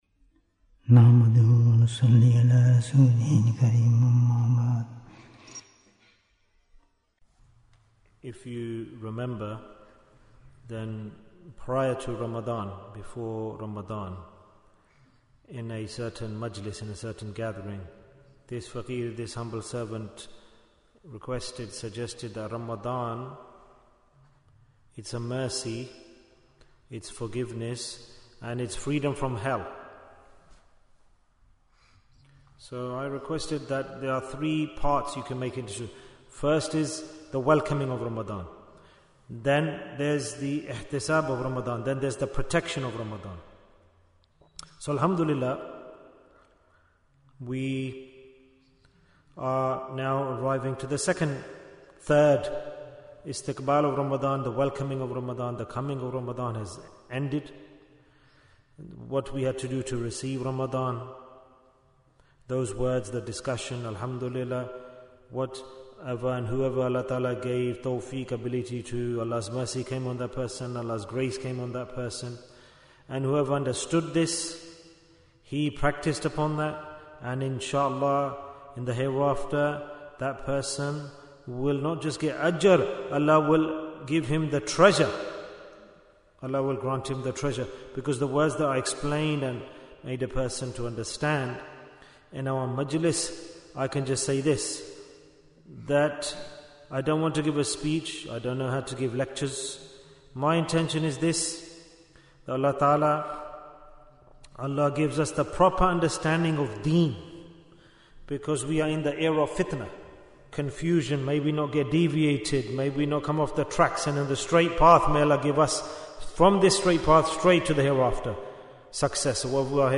Bayan, 61 minutes 9th March, 2025 Click for Urdu Download Audio Comments Bayan Episode 11 - Did You do Your Accounts in Ramadhan? Guests are a form of mercy, so we should value guests.